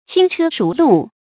注音：ㄑㄧㄥ ㄔㄜ ㄕㄨˊ ㄌㄨˋ
輕車熟路的讀法